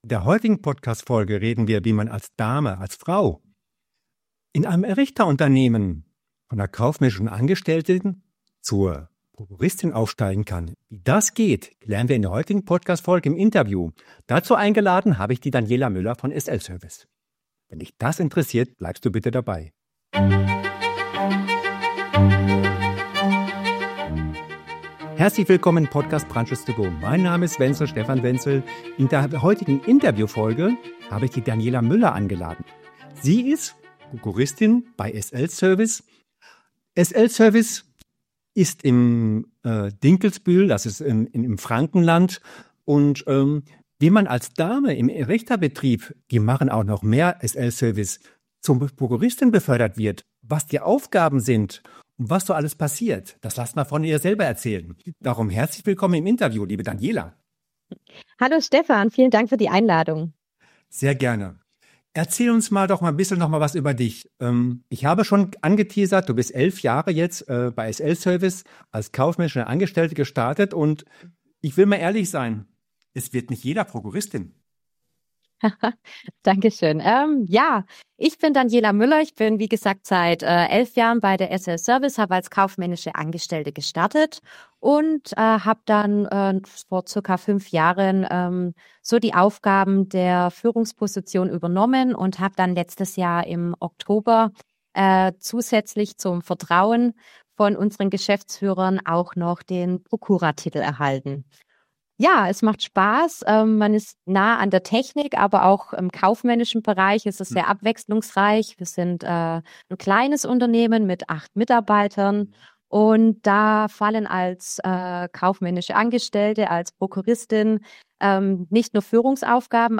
Zwischen IT, Videoüberwachung und Brandmeldeanlagen zeigt sie: Technik ist keine Männersache – sondern Einstellungssache. Ein inspirierendes Gespräch über Verantwortung, Teamgeist und Mut zum nächsten Schritt.